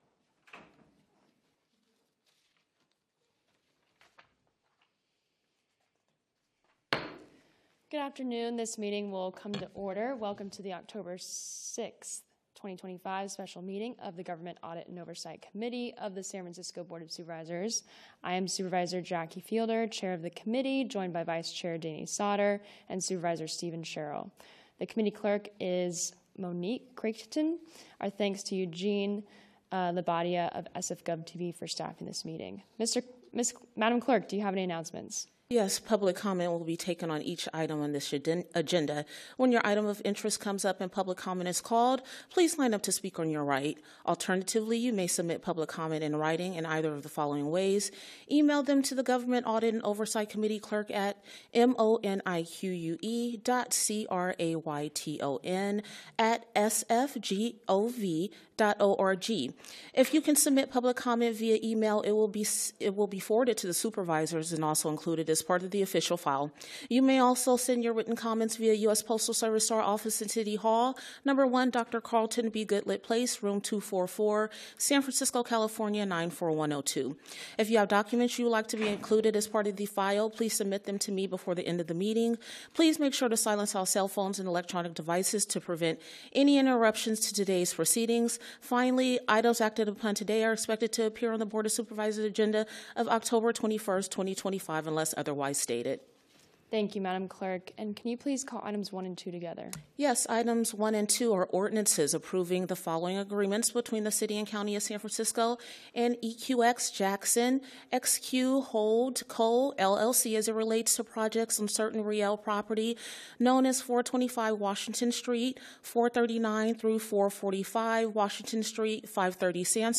BOS - Government Audit and Oversight Committee - Special Meeting - Oct 06, 2025